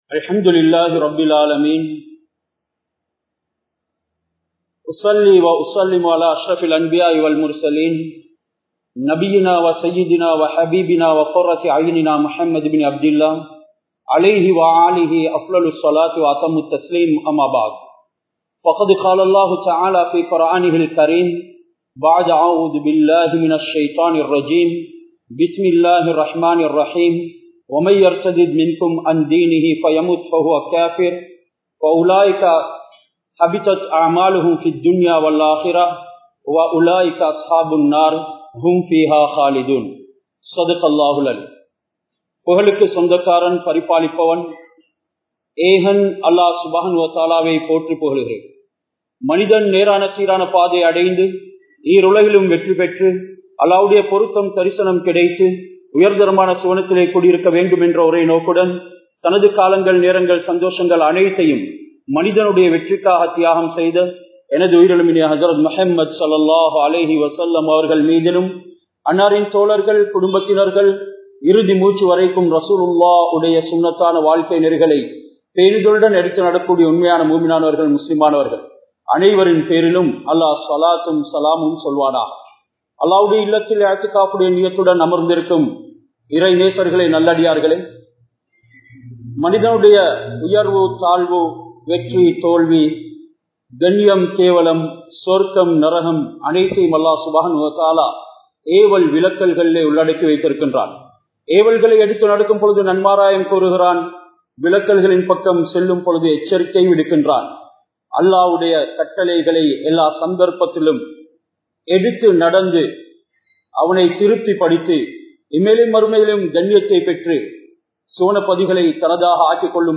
Dhunyavitkaaha Islaththai Ilappavarhale! (துன்யாவிற்காக இஸ்லாத்தை இழப்பவர்களே!) | Audio Bayans | All Ceylon Muslim Youth Community | Addalaichenai
Matala, Warakamura Jumua Masjidh